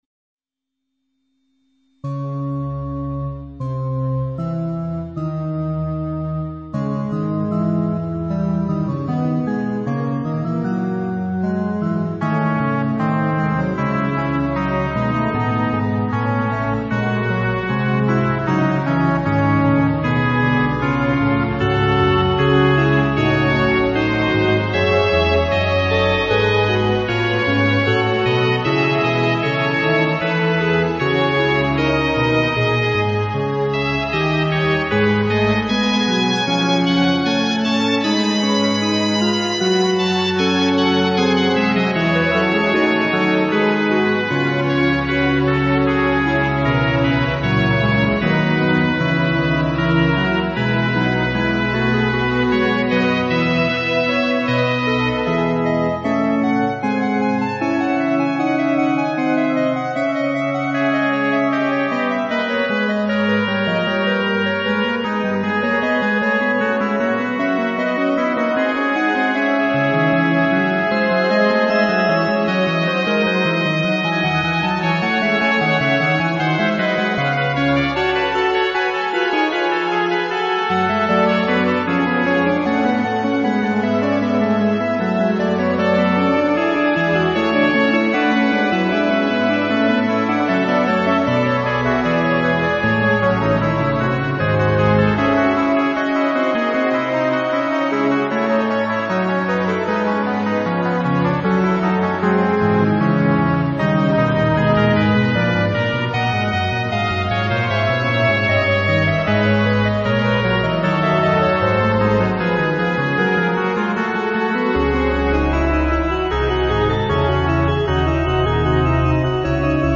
演奏はＭＳＸコンピューターの自動演奏です。
（約15年前に）本当はピアノ曲なんですが、“５声のフーガ”なので５パートをそれぞれ違う楽器の音で演奏させてみたらこんな風に仕上がったんです。